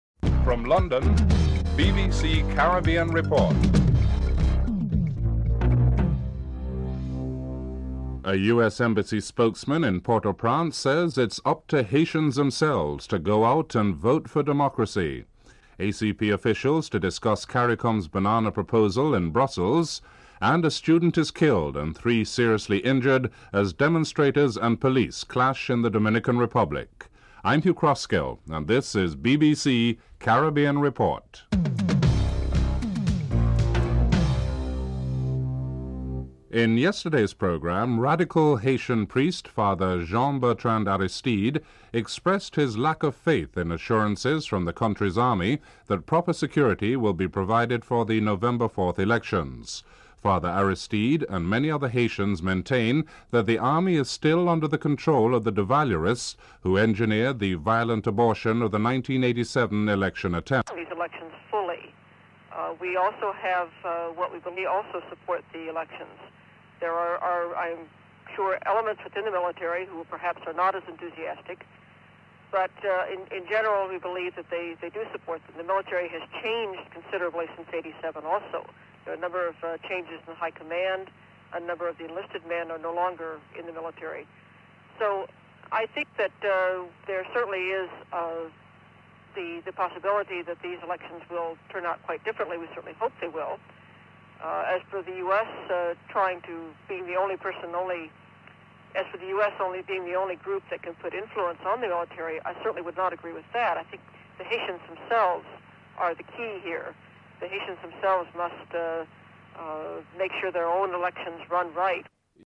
The British Broadcasting Corporation
1. Headlines (00:00-00:35)
6. ACP officials to discuss Caricom banana proposals in Brussels. Richard Gunn, OECS Ambassador to London (10:25-14:30)